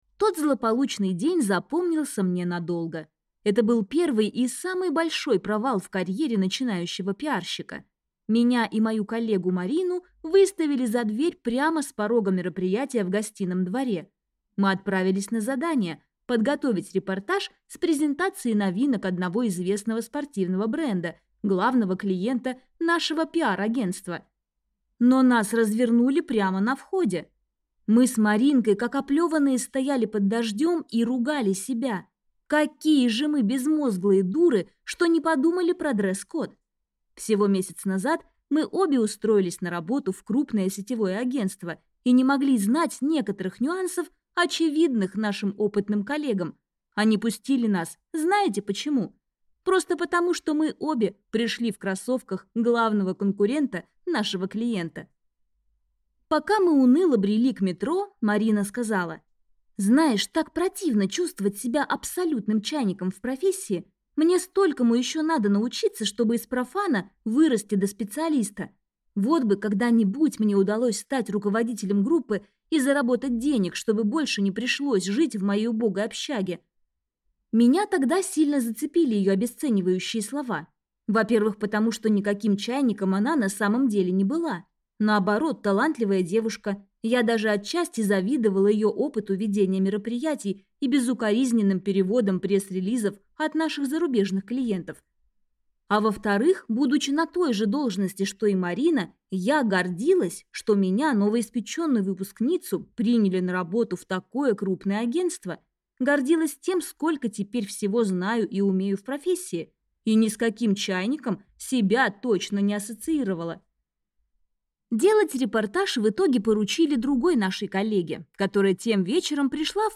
Аудиокнига Блог в помощь. Как зарабатывать на социальных сетях с маленькой аудиторией | Библиотека аудиокниг